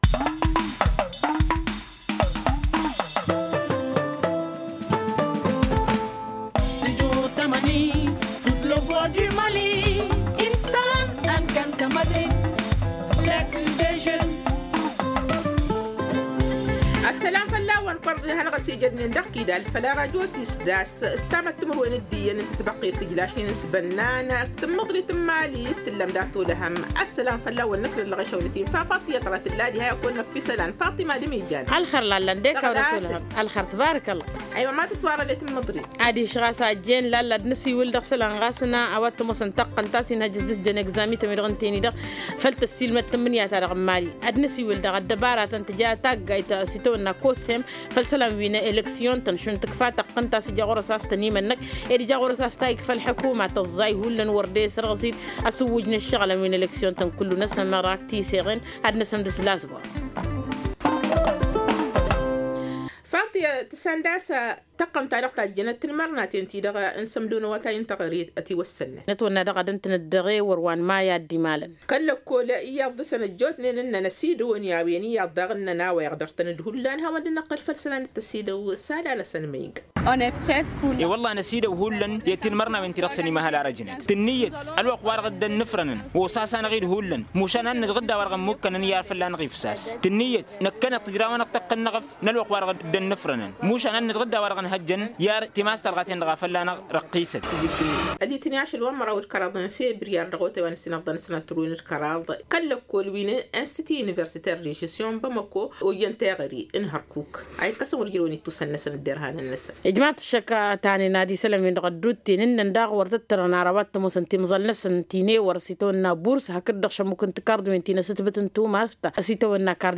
-Publication du calendrier des examens de fin d’année 2022-2023. Des candidats s’expriment dans cette édition.